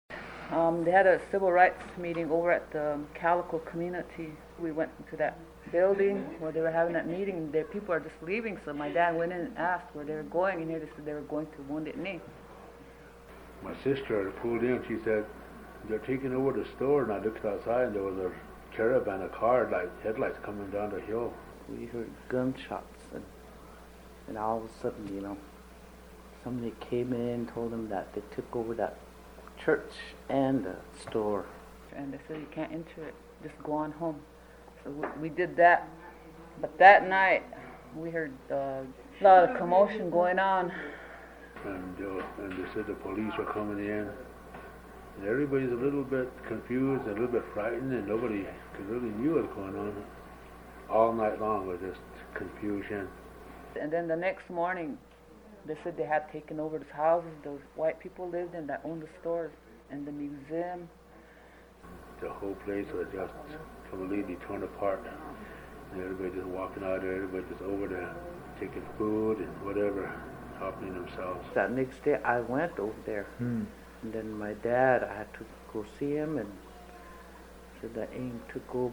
These three radio reports provide new insights on the occupation
Wounded Knee residents tell what they remember of the first night and the days after.